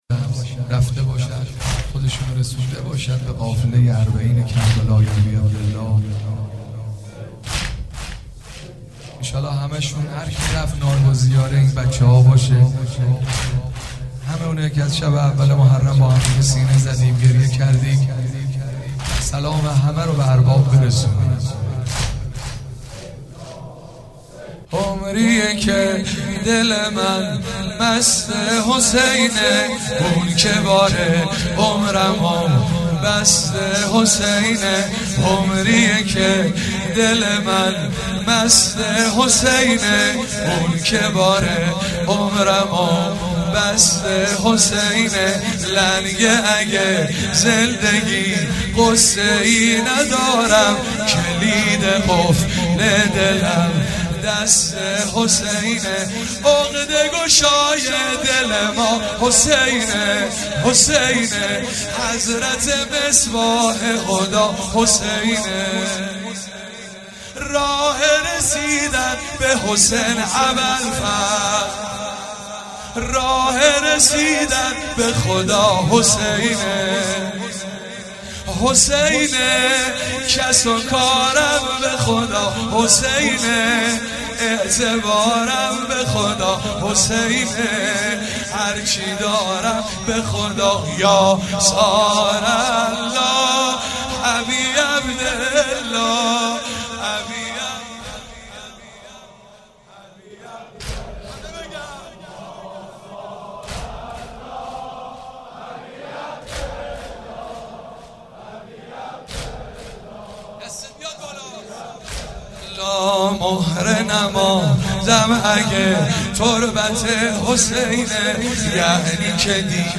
05 marasem 7 safar94 heiate alamdar mashhad alreza.mp3